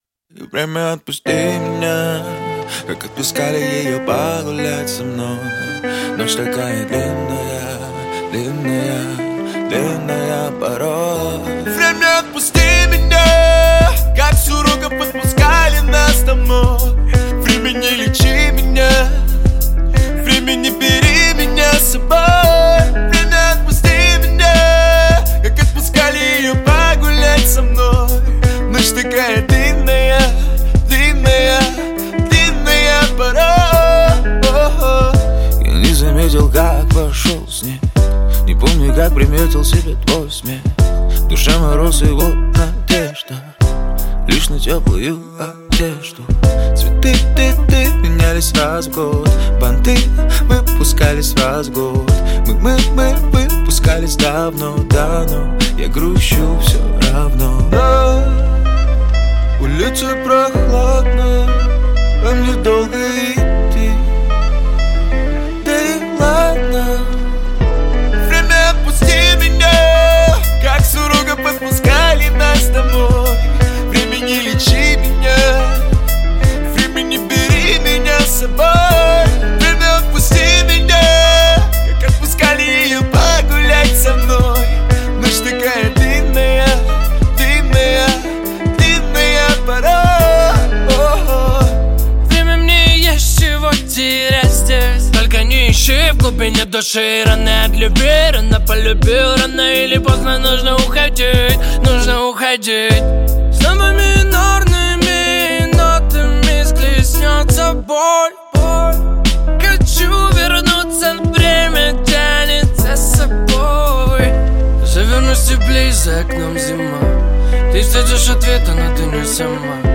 Жанр: Поп-музыка / Хип-хоп / Русский рэп